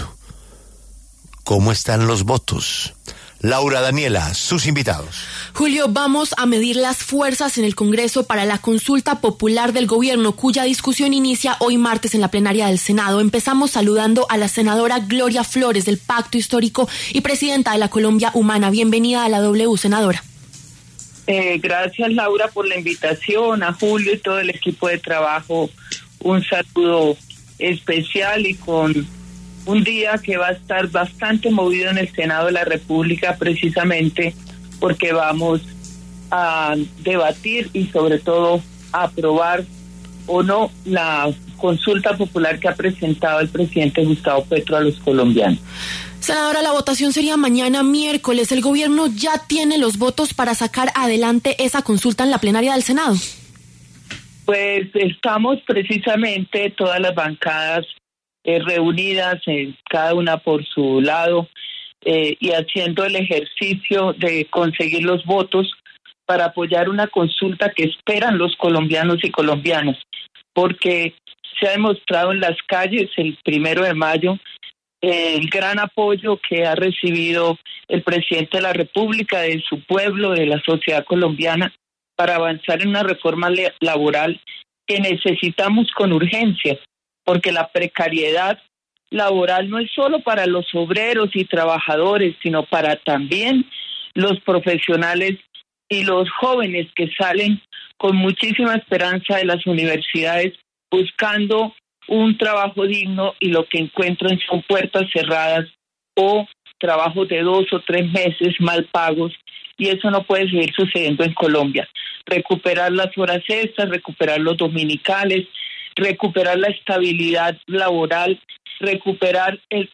Los senadores Gloria Flórez (Pacto Histórico), Alirio Barrera (Centro Democrático) y Ariel Ávila (Alianza Verde) pasaron por los micrófonos de La W.